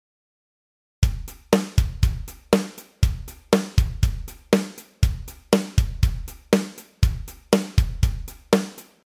8ビートの基本パターン2
基本パターン1の次に多用される、ドッタド　ドッタッのパータンです。
8beat2.mp3